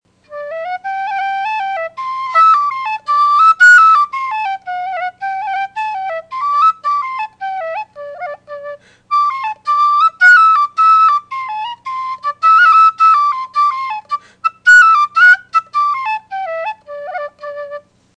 The D/C set I have has a husky tone. It’s rich and complex. This is definitely not a pure whistle, but one with character.
Garret Barry’s on the D whistle